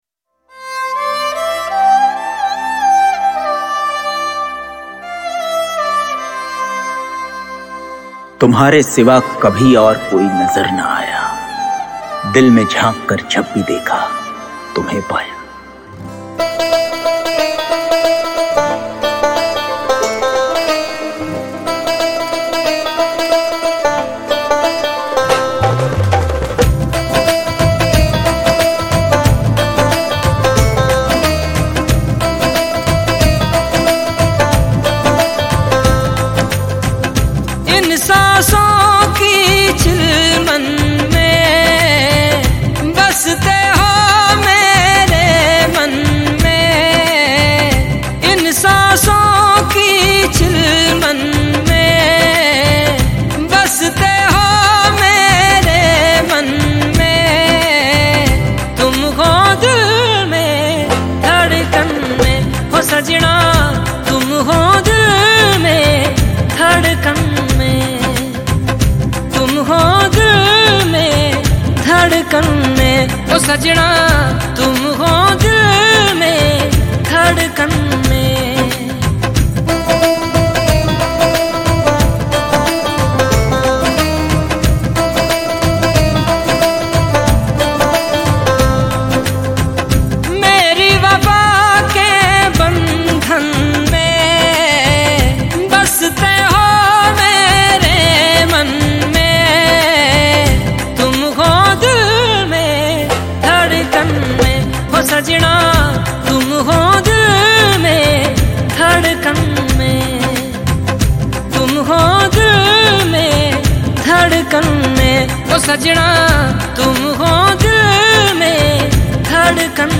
a hindi song
The beautiful song in sweet voice